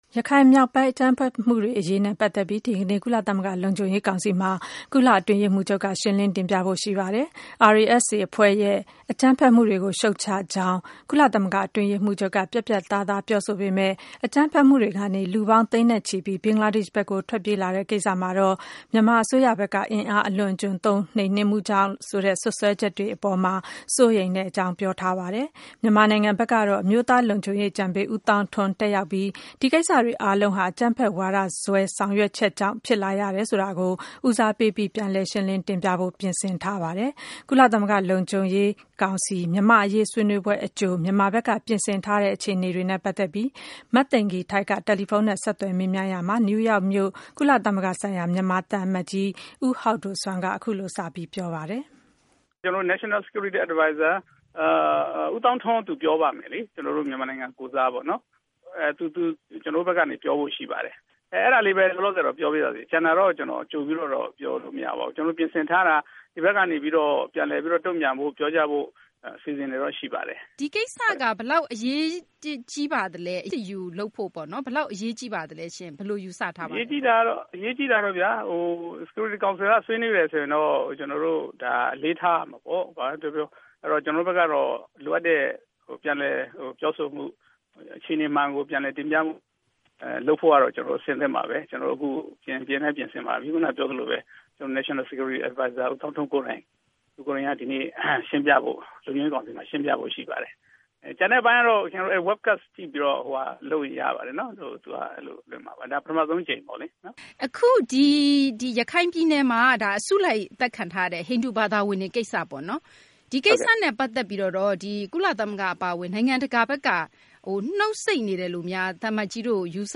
ရခိုင်မြောက်ပိုင်း အကြမ်းဖက်မှုတွေ အရေးနဲ့ ပတ်သက်ပြီး ဒီကနေ့ ကုလသမဂ္ဂ လုံခြုံရေးကောင်စီမှာ ကုလ အတွင်းရေးမှူးချုပ်က ရှင်းလင်း တင်ပြမှာ ဖြစ်ပါတယ်။ ARSA အဖွဲ့ရဲ့ အကြမ်းဖက်မှုတွေကို ရှုတ်ချကြောင်း ကုလသမဂ္ဂ အတွင်းရေးမှူးချုပ်က ပြတ်ပြတ်သားသား ပြောဆိုပေမယ့် အကြမ်းဖက်မှုတွေကနေ လူပေါင်း သိန်းနဲ့ချီပြီး ဘင်္ဂလားဒေရှ့်ဘက်ကို ထွက်ပြေးလာတဲ့ ကိစ္စမှာတော့ မြန်မာ အစိုးရဘက်က အင်အားအလွန်အကျွံသုံး နှိမ်နင်းမှုကြောင့် ဆိုတဲ့ စွပ်စွဲချက်တွေ အပေါ်မှာ စိုးရိမ်တဲ့ အကြောင်း ပြောဆိုထားတာပါ။မြန်မာနိုင်ငံဘက်ကတော့ အမျိုးသား လုံခြုံရေး အကြံပေး ဦးသောင်းထွန်း တက်ရောက်ပြီး ဒီကိစ္စတွေ အားလုံးဟာ အကြမ်းဖက်ဝါဒစွဲ ဆောင်ရွက်ချက်တွေကြောင့် ဖြစ်လာရတယ်ဆိုတာကို ဦးစားပေးပြီး ပြန်လည် ရှင်းလင်း တင်ပြဖို့ ပြင်ဆင်ထားပါတယ်။ ကုလလုံခြုံရေးကောင်စီ မြန်မာ့အရေး ဆွေးနွေးပွဲ အကြို မြန်မာဘက်က ပြင်ဆင်ထားတဲ့ အခြေအနေတွေနဲ့ ပတ်သက်ပြီး နယူးယောက်မြို့ ကုလသမဂ္ဂ ဆိုင်ရာ မြန်မာ သံအမတ်ကြီး ဦးဟောက်ဒိုဆွမ်းက အခုလို စပြီး ပြောကြားပါတယ်။